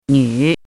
汉字“钕”的拼音是：nǚ。
钕的拼音与读音
nǚ.mp3